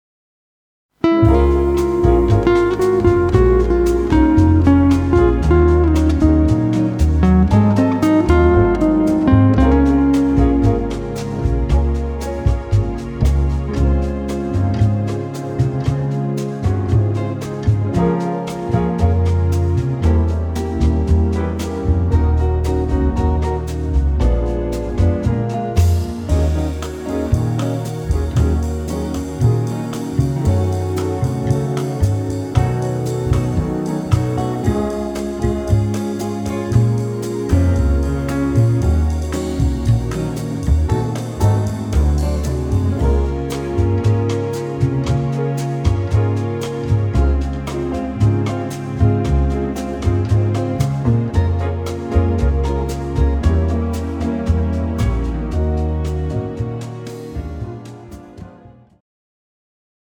bossanova style
tempo 115 bpm
female backing track
This track is in medium tempo bossanova style.